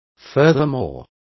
Complete with pronunciation of the translation of furthermore.